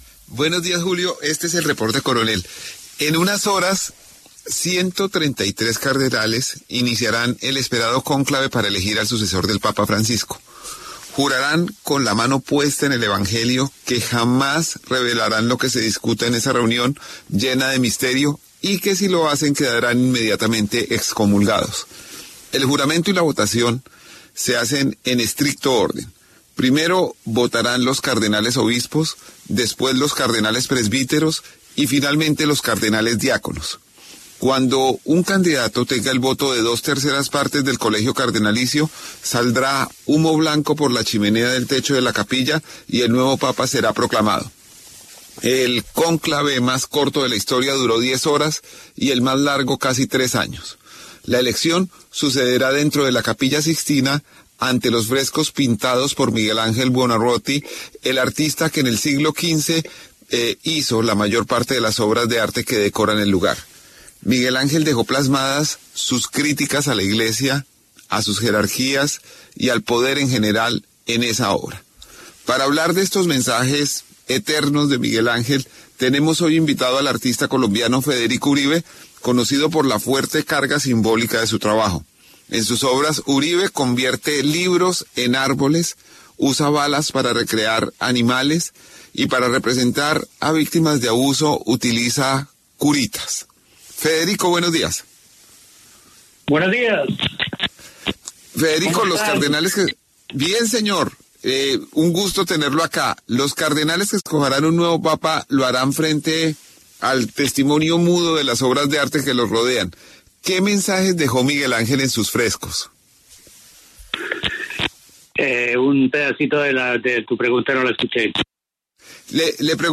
El artista colombiano Federico Uribe habla de las obras que rodearán a los electores del nuevo papa.
Para hablar de estos mensajes eternos de Miguel Ángel tuvimos invitado al artista colombiano Federico Uribe, conocido por la fuerte carga simbólica de su trabajo.